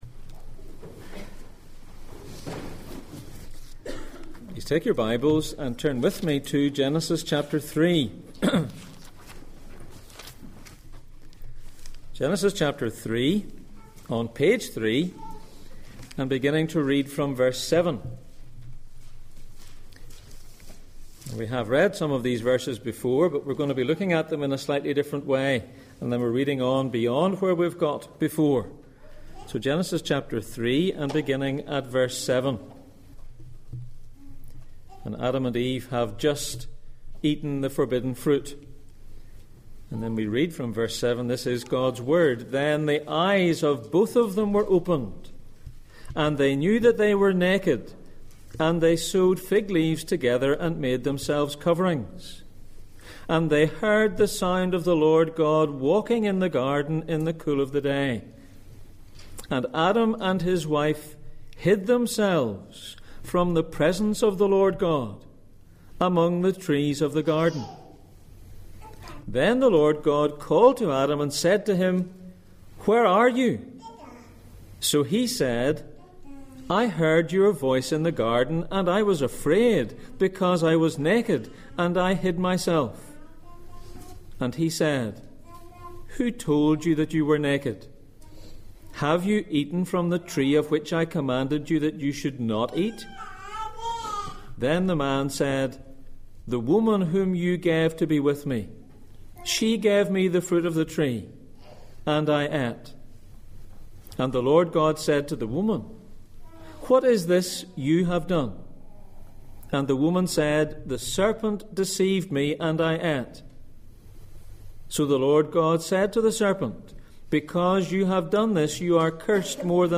Back to the beginning Passage: Genesis 3:7-19, Isaiah 53:4-5 Service Type: Sunday Morning